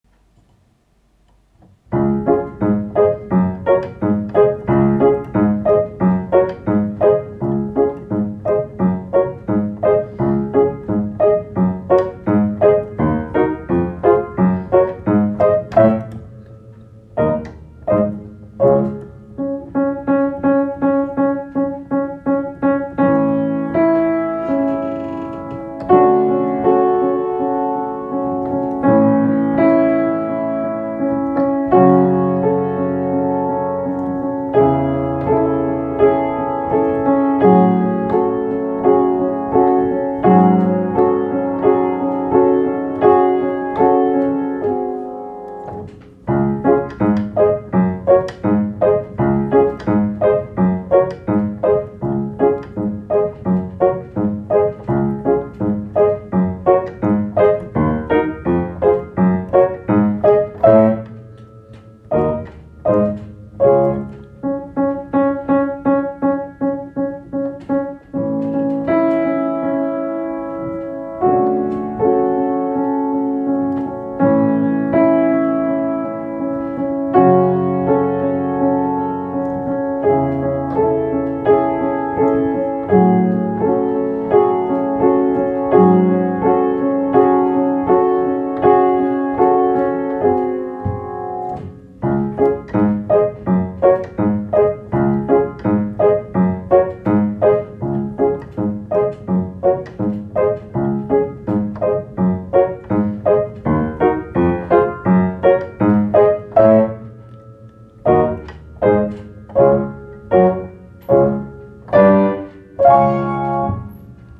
Klikněte ZDE - PAMPELIŠKY / KLAVÍRNÍ DOPROVOD V TEMPU